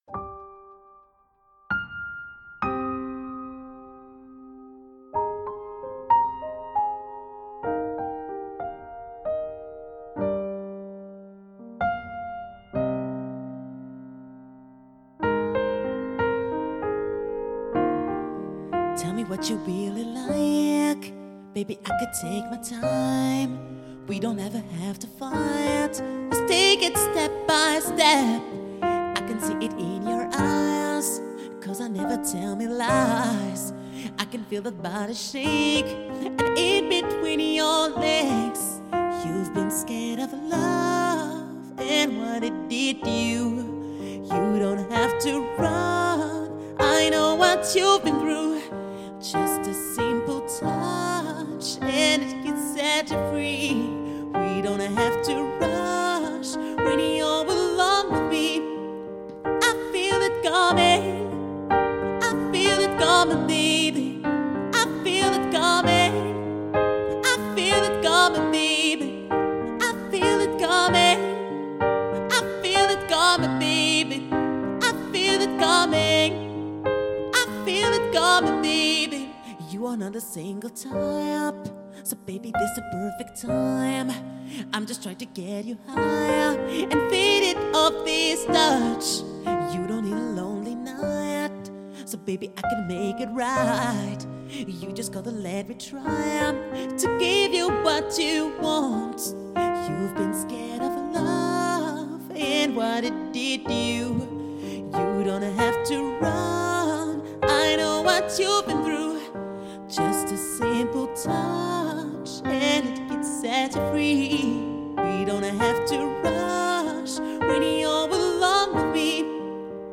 Répertoire : Pop, Jazz
Parfait pour : Piano-Bar, Cocktail, Lounge